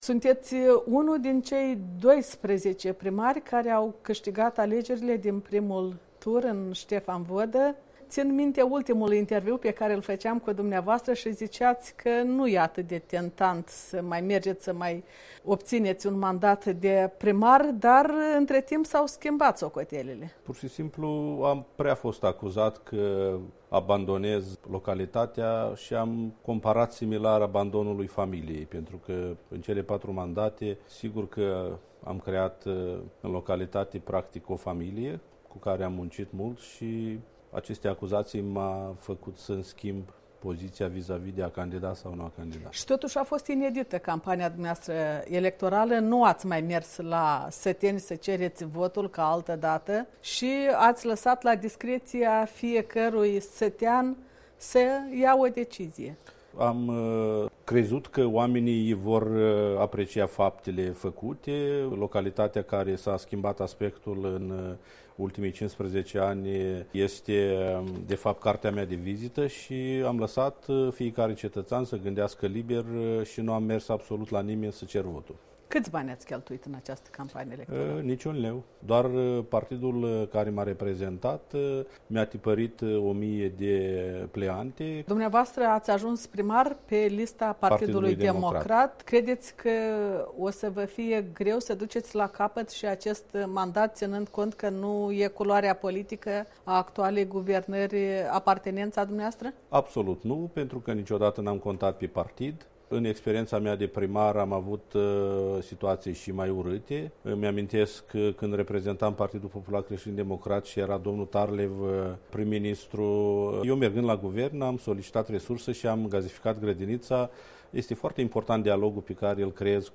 Interviu cu primarul de la Feşteliţa, Ştefan Vodă, care a câștigat al patrulea mandat.